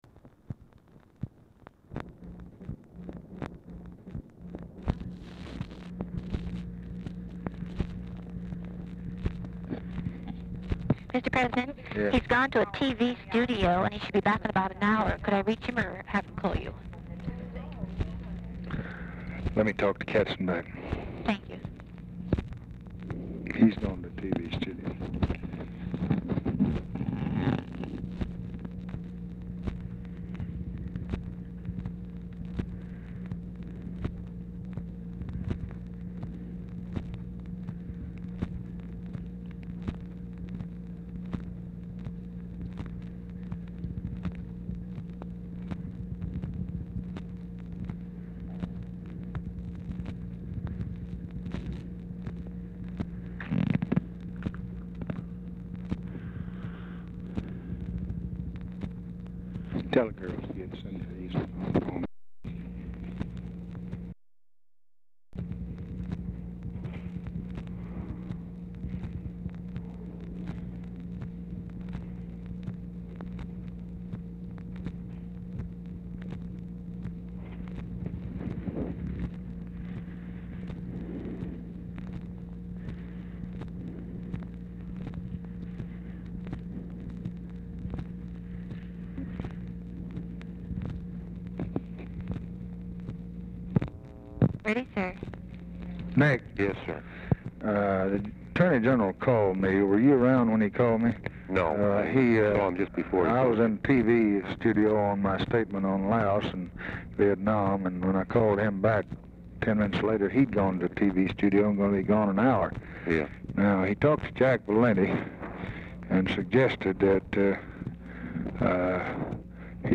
Telephone conversation # 3832, sound recording, LBJ and NICHOLAS KATZENBACH, 6/23/1964, 3:35PM | Discover LBJ
Format Dictation belt
Location Of Speaker 1 Oval Office or unknown location
Other Speaker(s) TELEPHONE OPERATOR, OFFICE CONVERSATION